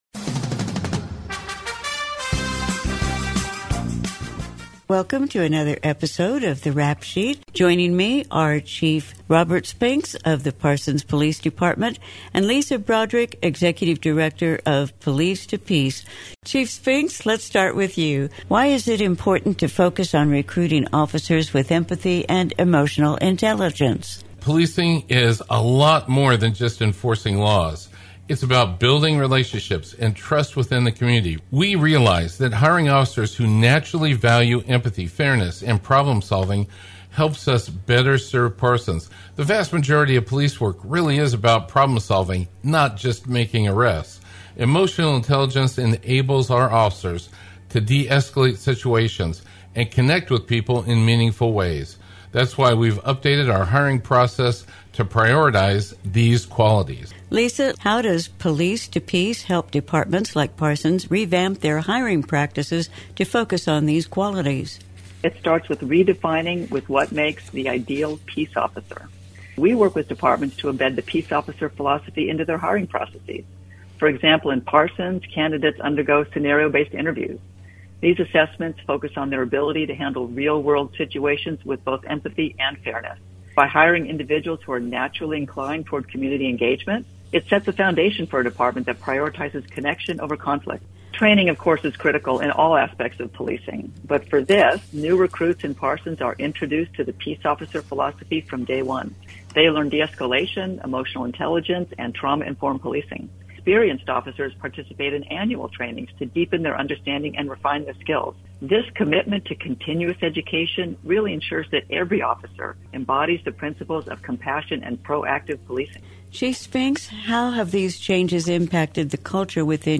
The Rap Sheet will be a reoccurring Podcast with the Parsons Police Chief Robert Spinks, and occasional guests, covering a variety of topics with the community and letting citizens know about upcoming events activities and information. The 'Rap Sheet' is aired on Wednesdays at 7:30am on 106.7 FM and 1540 AM V93KLKC.